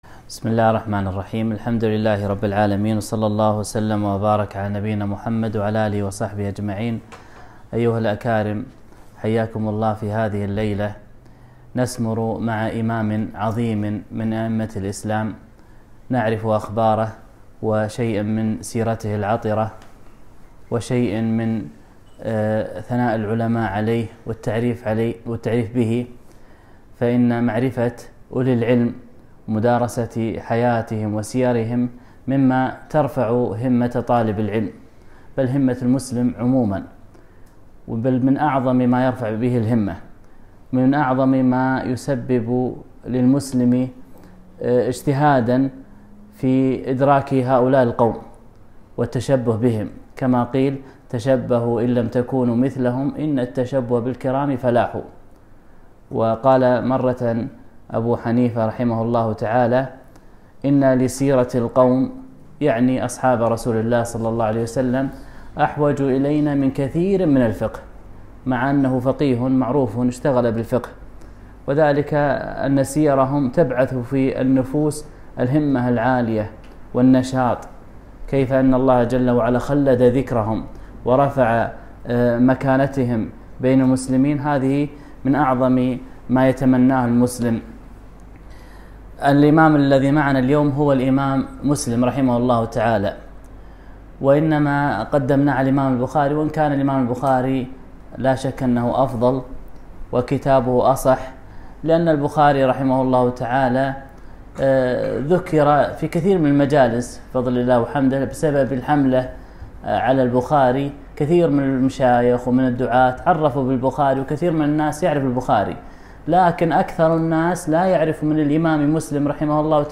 محاضرة - سيرة الإمام مسلم رحمه الله